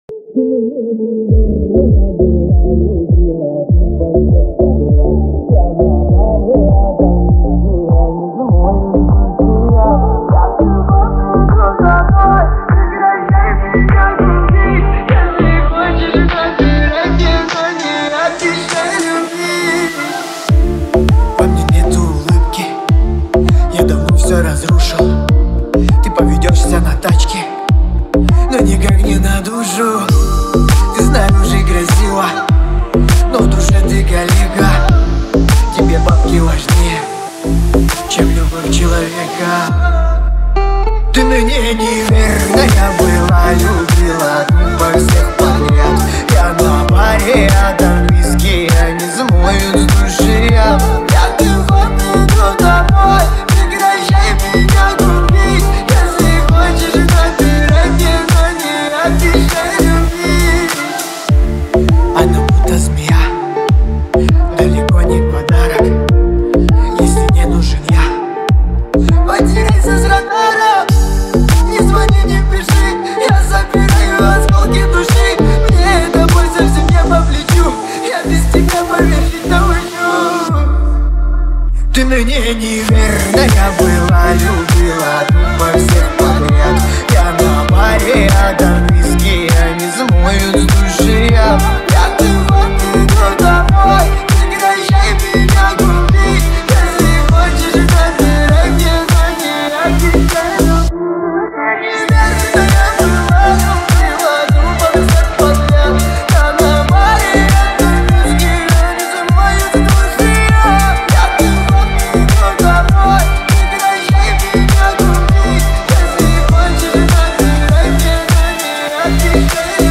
• Качество: 320 kbps, Stereo
ремикс